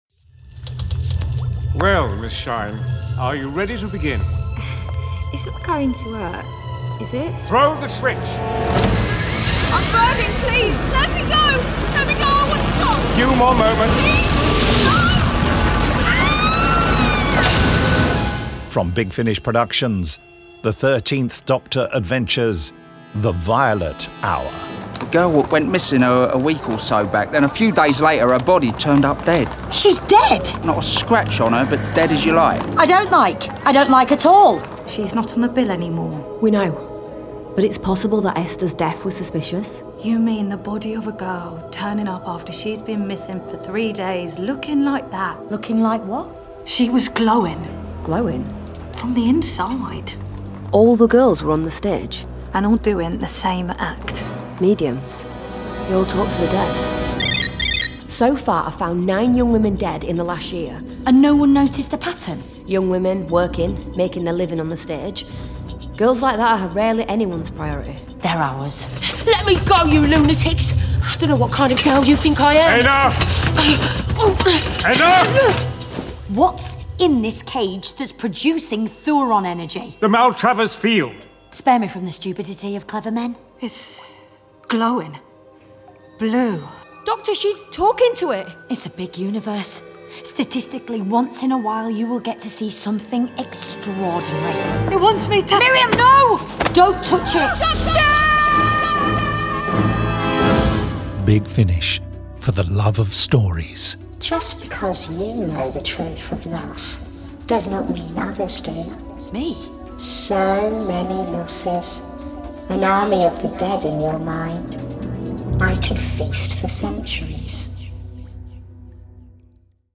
Starring Jodie Whittaker Mandip Gill